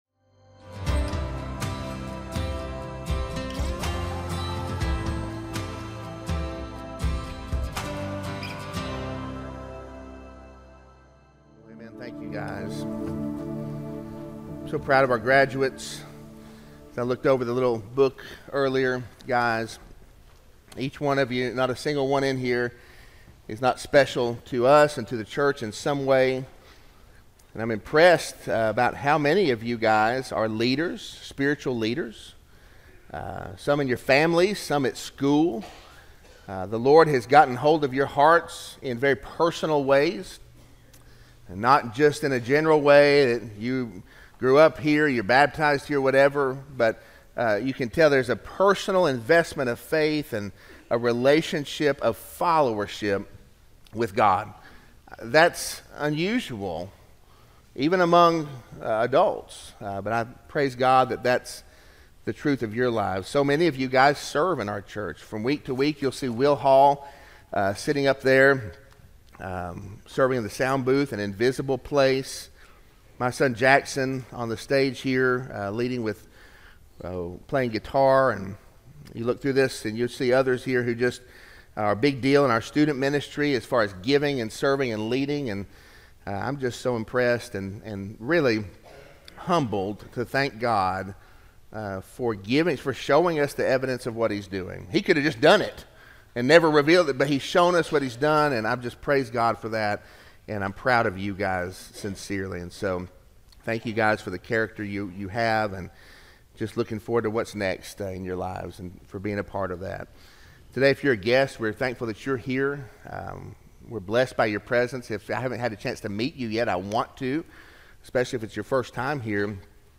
Sermon-5-5-24-audio-from-video.mp3